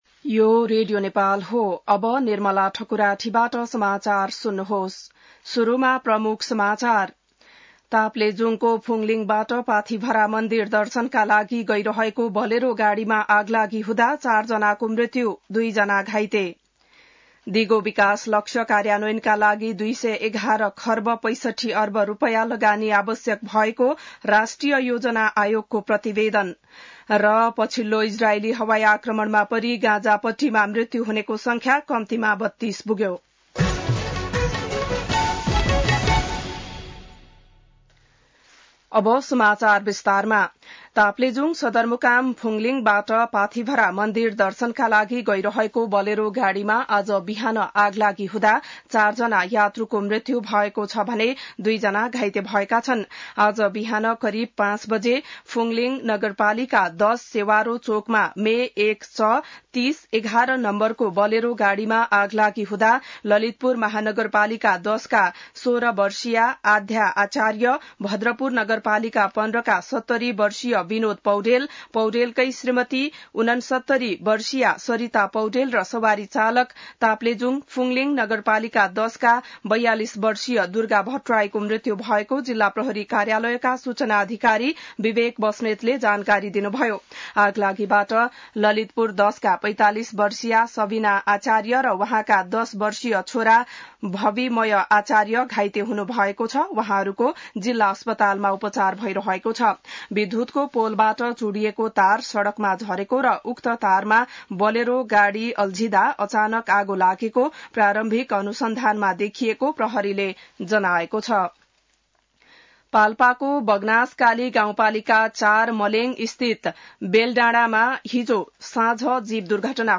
बिहान ९ बजेको नेपाली समाचार : ८ वैशाख , २०८२